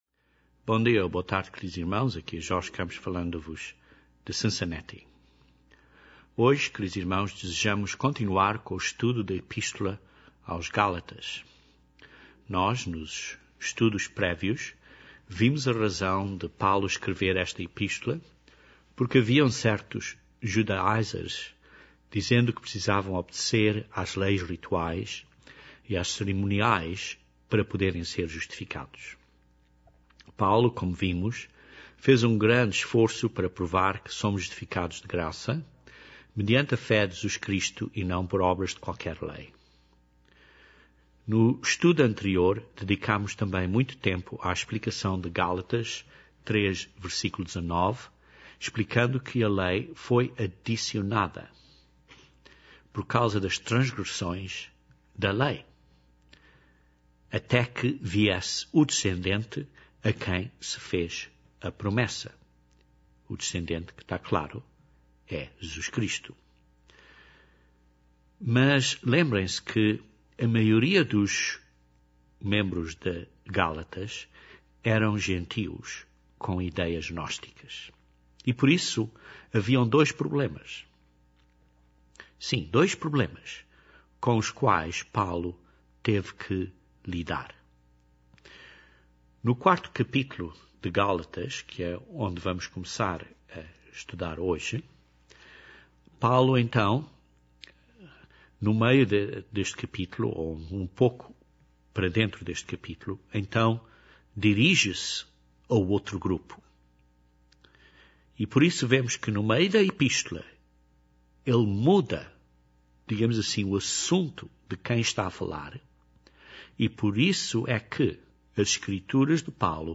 Para aprender mais desta secção da epístola de Paulo, ouça este estudo bíblico.